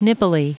Prononciation du mot nippily en anglais (fichier audio)
Prononciation du mot : nippily